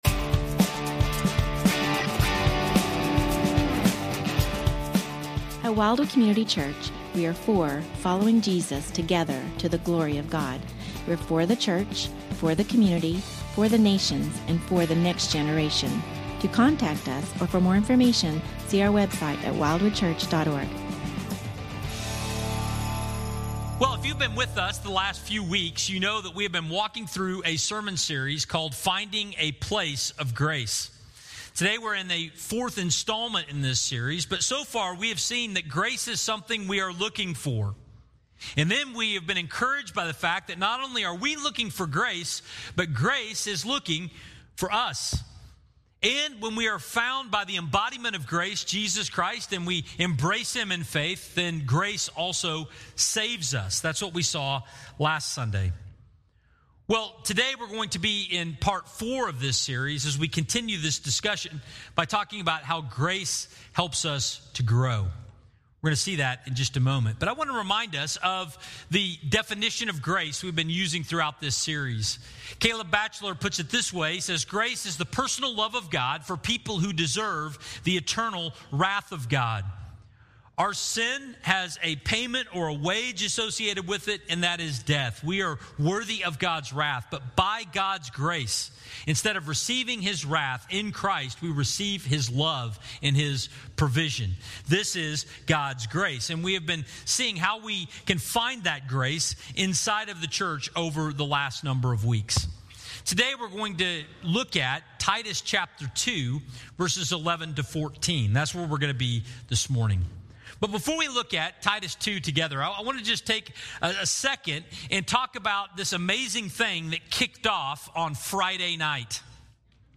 Finding a Place of Grace (part 4) Sermon Audio, Video, & Questions